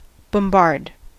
Ääntäminen
US : IPA : [bəm.ˈbɑɹd]